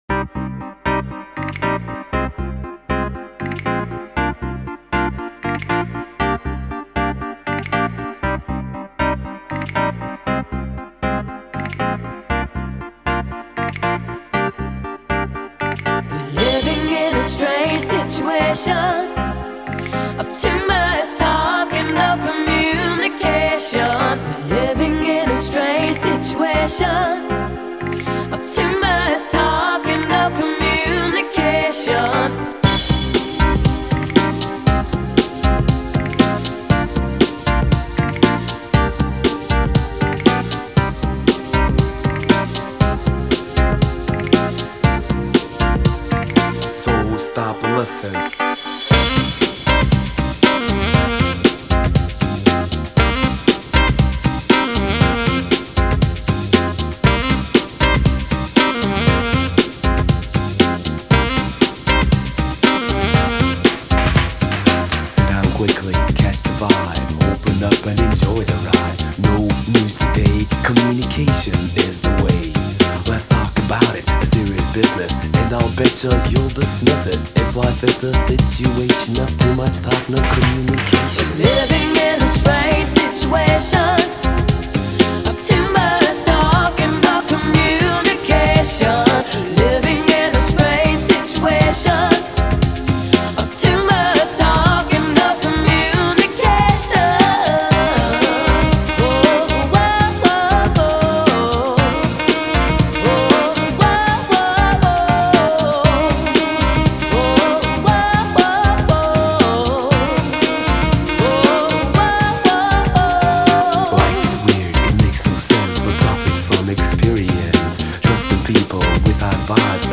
Стильненькая старая песенка